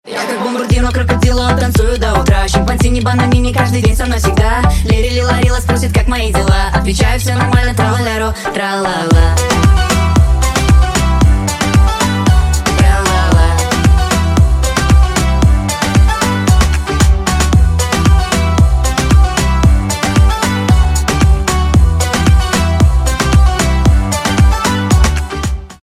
2025 » Новинки » Русские » Поп Скачать припев